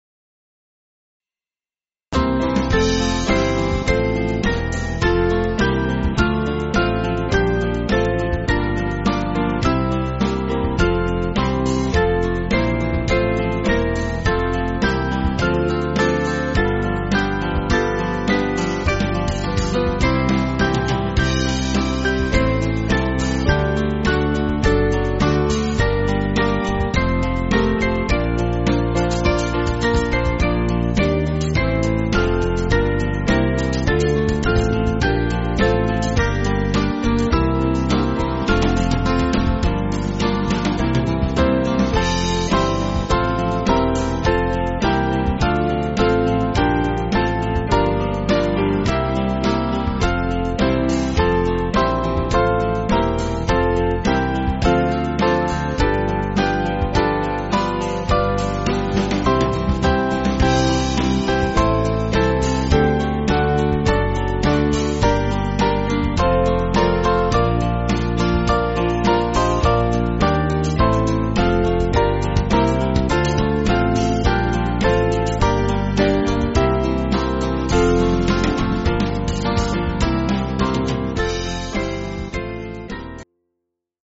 8.7.8.7.D
Small Band